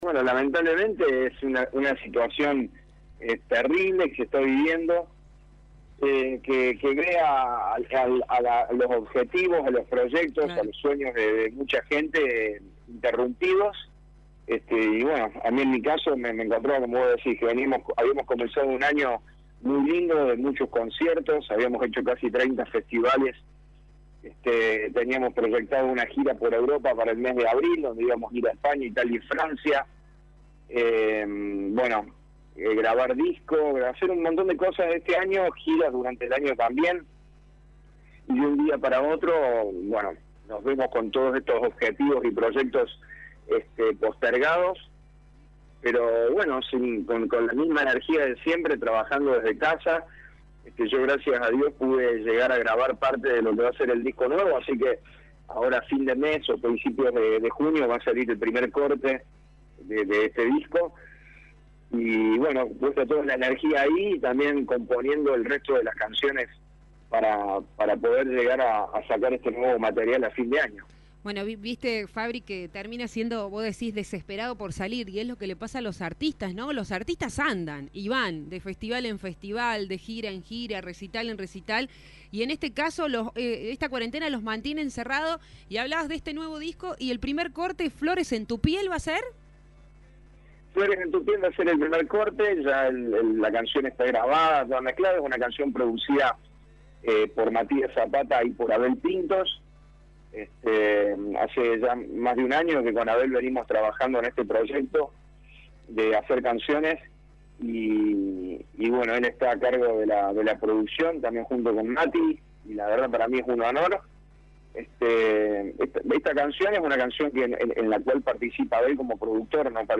lo reflejó en la entrevista con Radio Show.